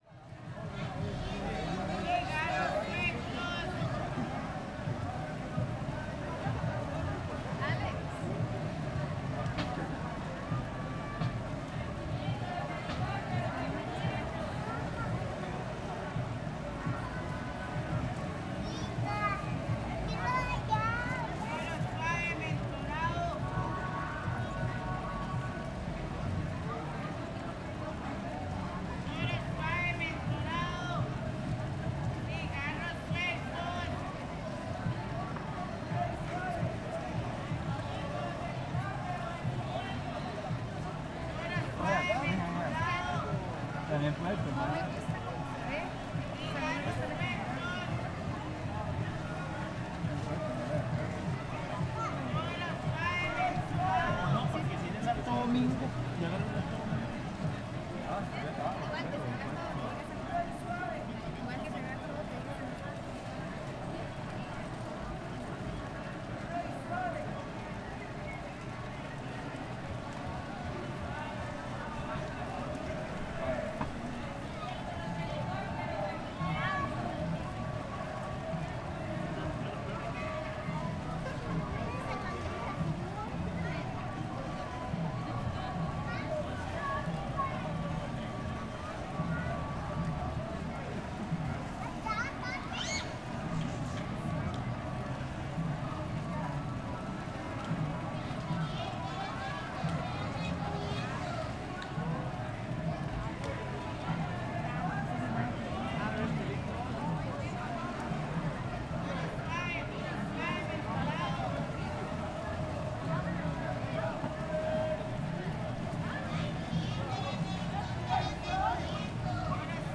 Pregones dentro de la O Noche
Se escucha la fuente del reloj, música lejana (una flauta y un bajo rítmico en un altoparlante), transeúntes, y pregones (mujeres principalmente, vendiendo cigarros Derby duro, suave o mentolado, y helicópteros de juguete a 500 colones.
dcterms.audio.microphoneZoom H6es_ES
Pregones dentro de la O Noche ACM 2018.wav